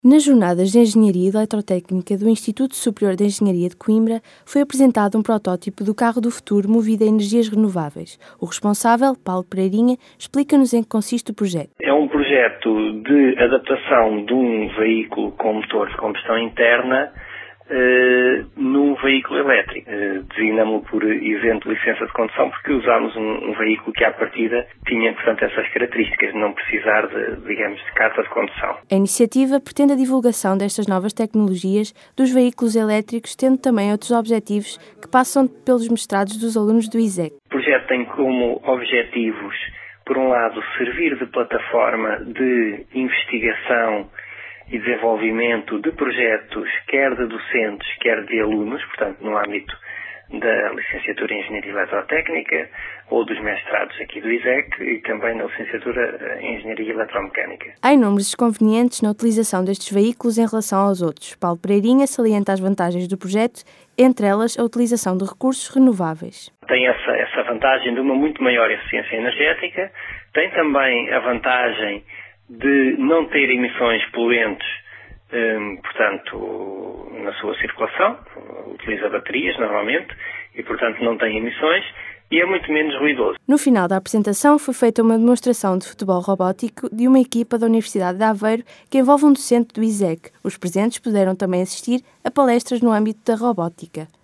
Noticiário